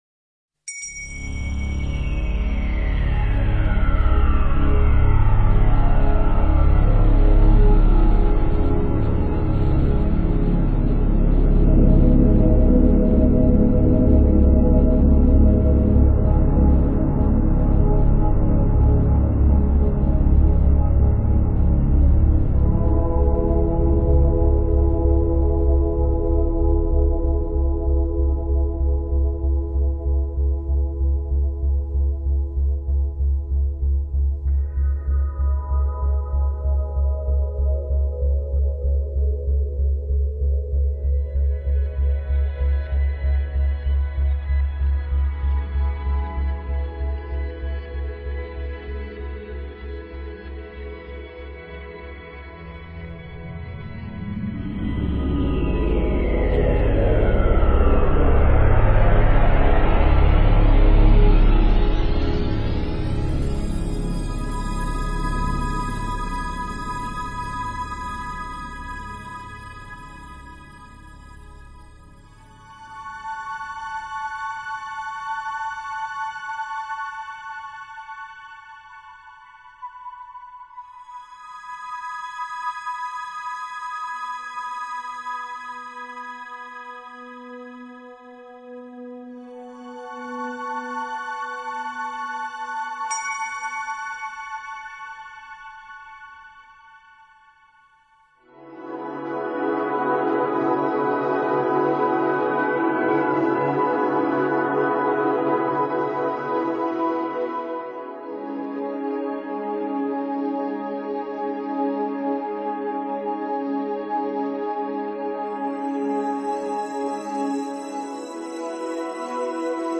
最后，在雄浑的祷告中，万物共枕同眠……唯有“月”，是梦里心里一颗永不坠落的晓星！